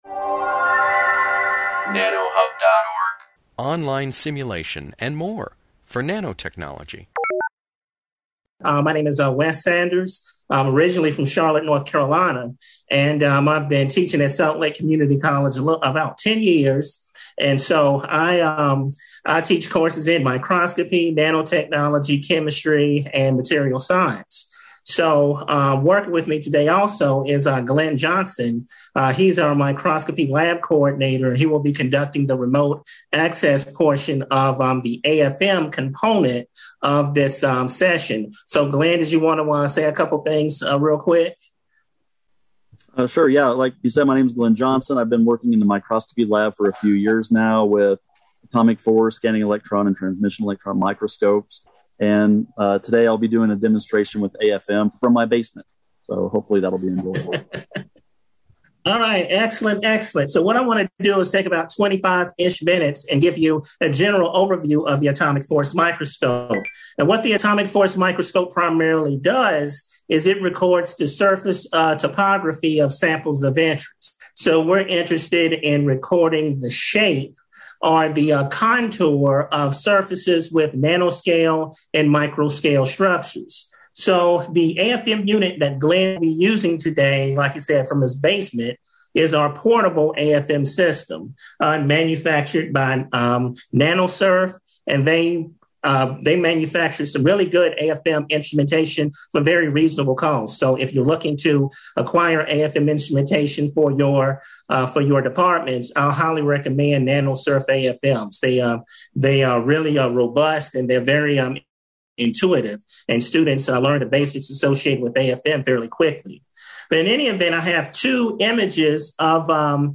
This webinar, published by the Nanotechnology Applications and Career Knowledge Support (NACK) Center at Pennsylvania State University, covers atomic force microscopy and the atomic force microscope (AFM).